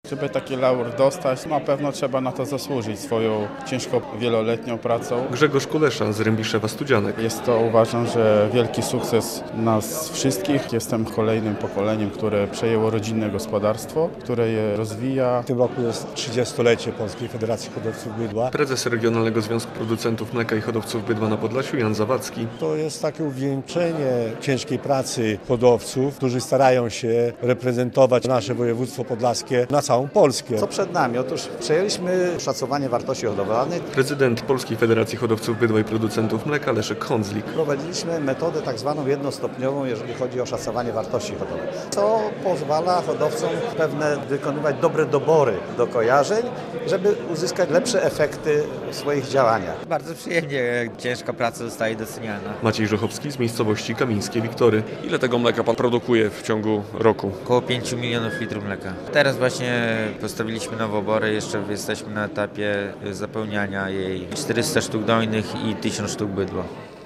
Gala wręczenia Podlaskich Mlecznych Laurów - relacja